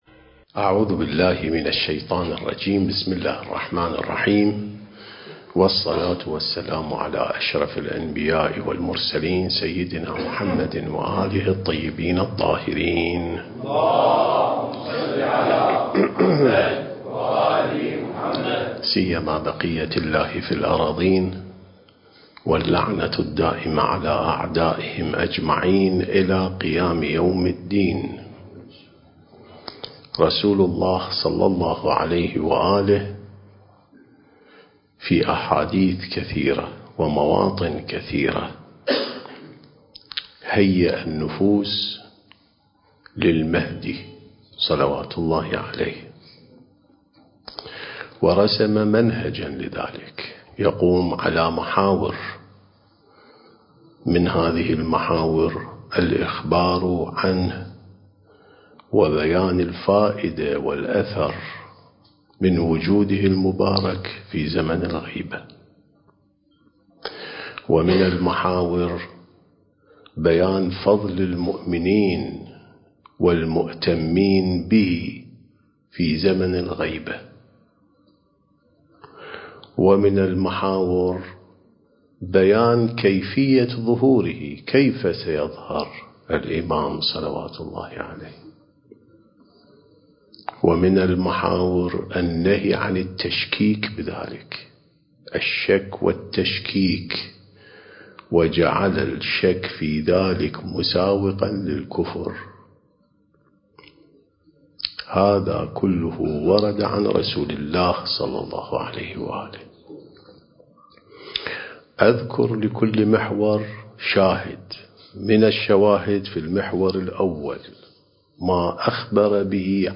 سلسلة محاضرات: الإعداد الربّاني للغيبة والظهور (5)